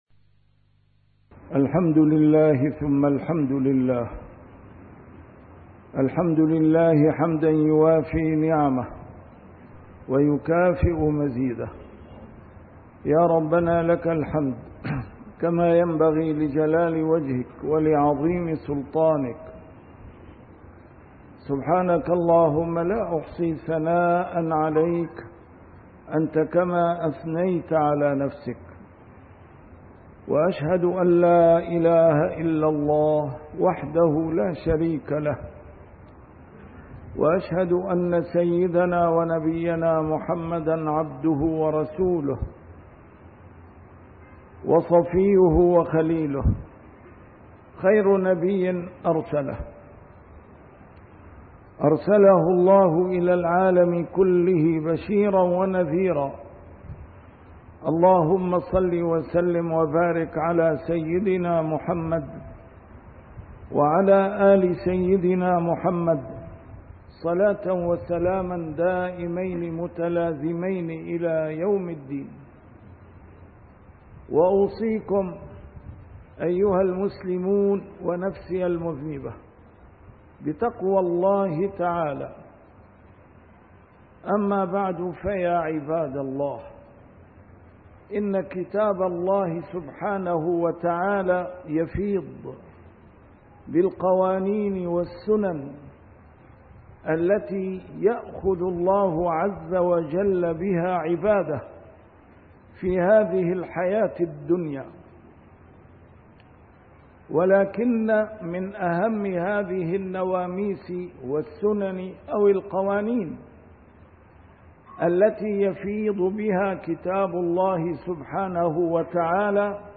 A MARTYR SCHOLAR: IMAM MUHAMMAD SAEED RAMADAN AL-BOUTI - الخطب - لهذا يتطوح العرب بأودية الذل